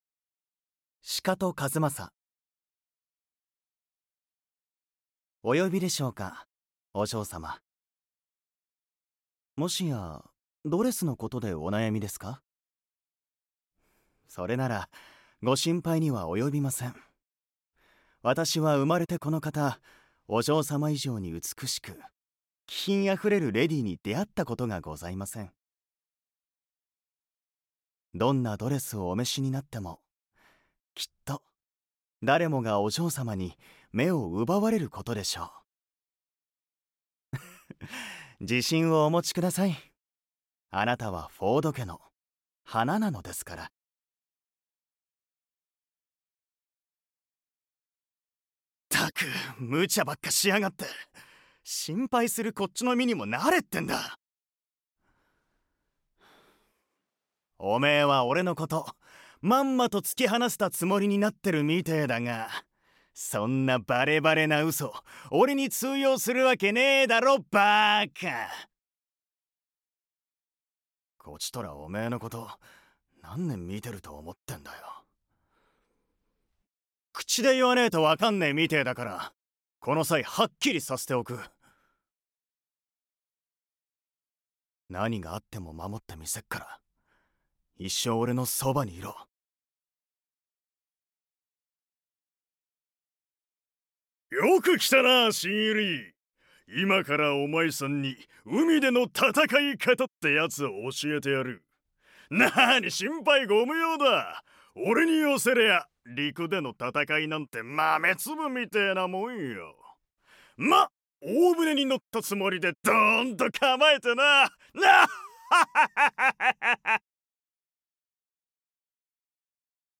サンプルボイス
北海道弁